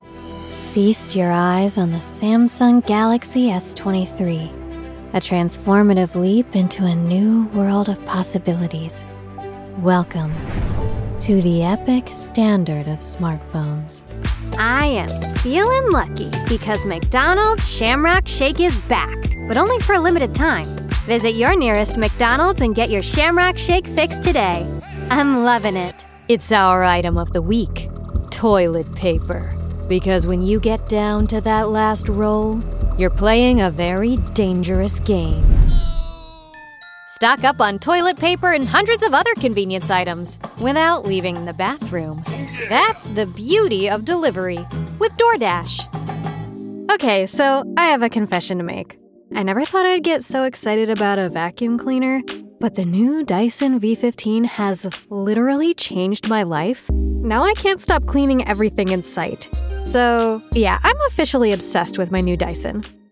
Wonderfully Weird. Fresh. Unconventional
Commercial
I have a wonderfully weird, bouncy voice that inspires and delights my collaborators.